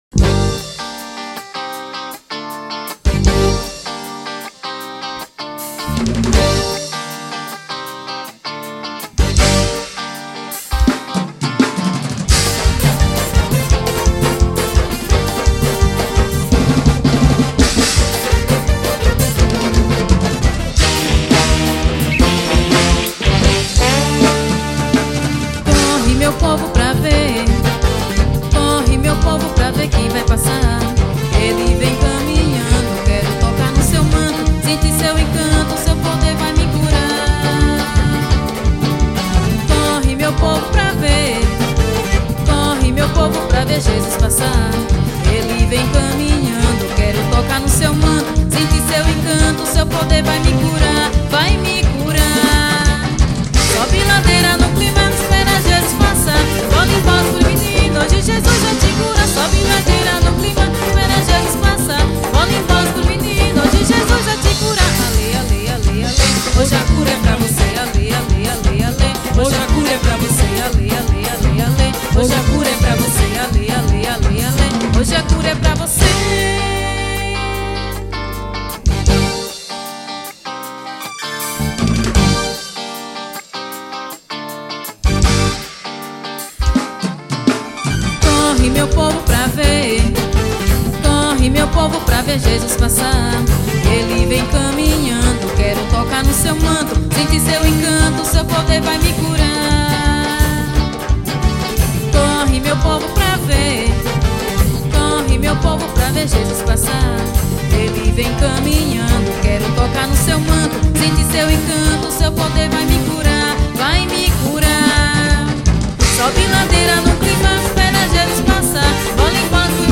gospel.